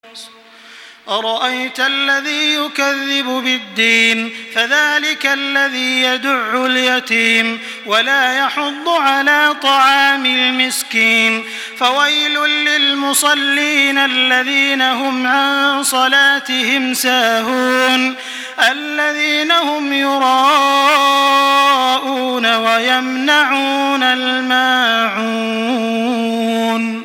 Listen and download the full recitation in MP3 format via direct and fast links in multiple qualities to your mobile phone.
دانلود سوره الماعون توسط تراويح الحرم المكي 1425
مرتل